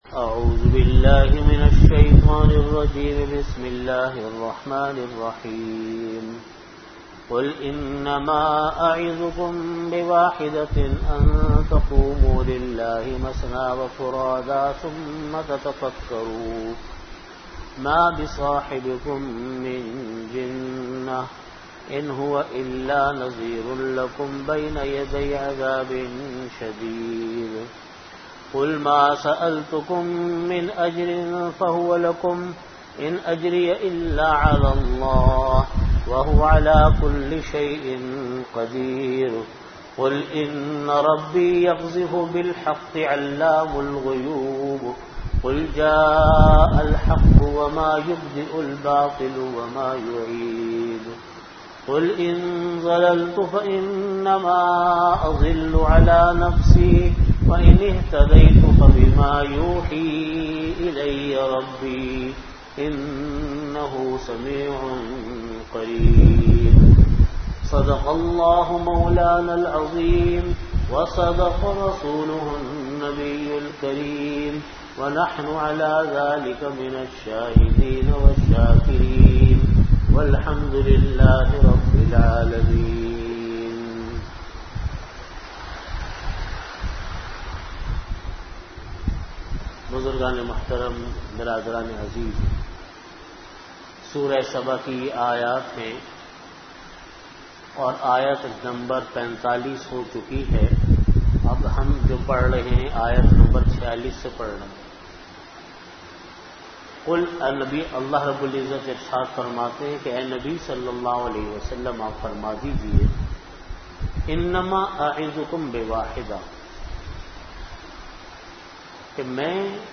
Tafseer · Jamia Masjid Bait-ul-Mukkaram, Karachi